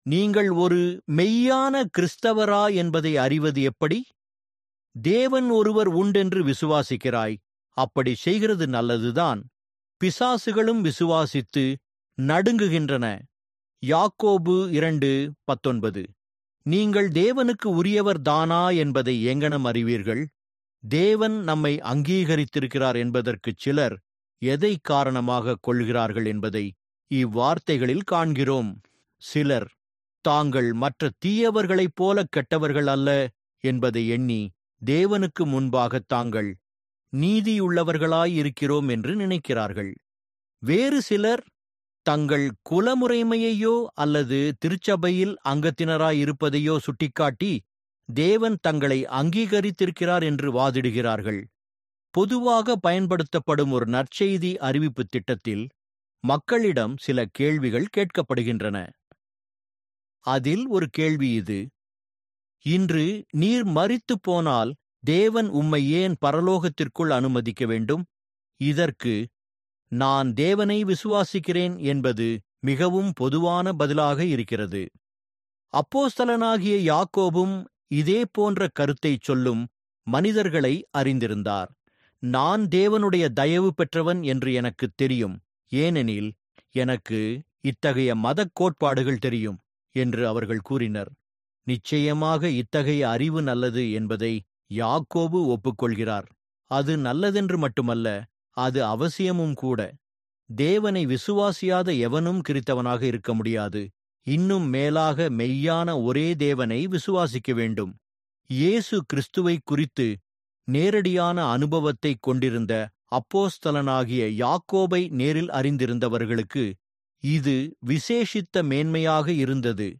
ஜொனாதன் எட்வர்ட்ஸ் (மொழிபெயர்ப்பு: ஜெமினி AI)
பிரசங்கம் நீங்கள் ஒரு மெய்யான கிறிஸ்தவரா என்பதை அறிவது எப்படி?